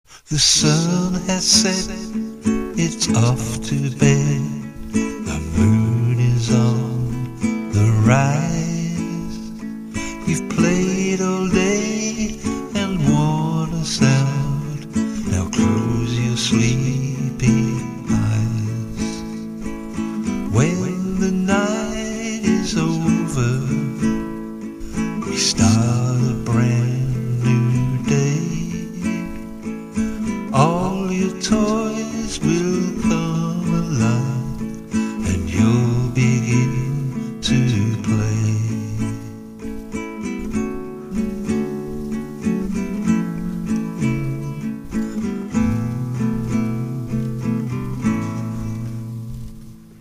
The first song is a lullaby, it uses the chords Dmaj7, A, Esus4.
lullaby.mp3